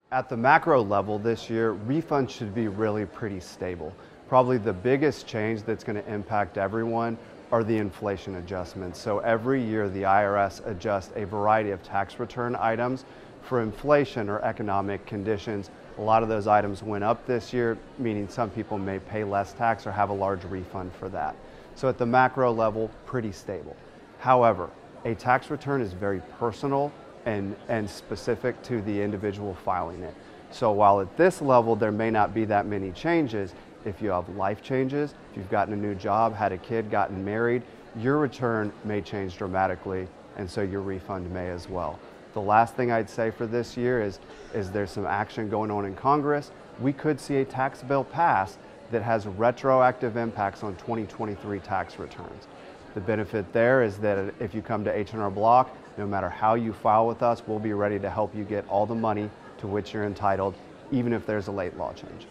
Insert this audio file of an H&R Block tax expert into your broadcast story on refund sizes.